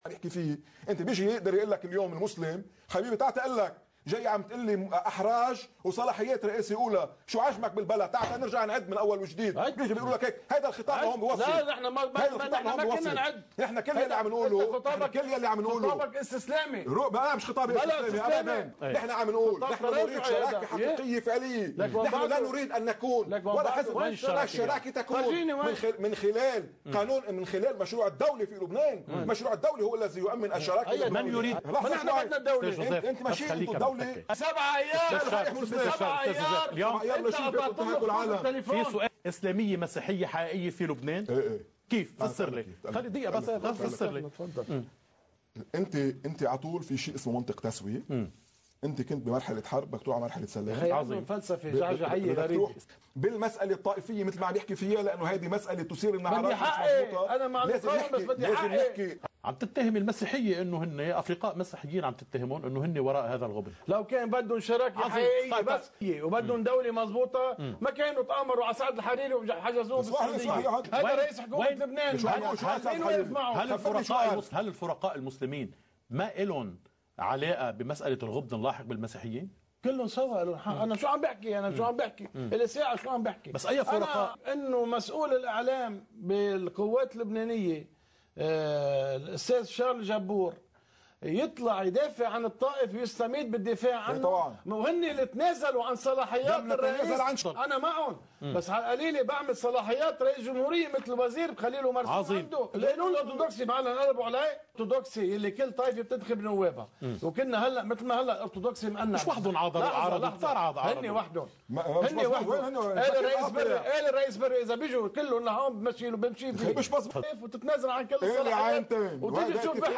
مقتطف من حديث
لقناة الـ”LBC” ضمن برنامج “نهاركم سعيد”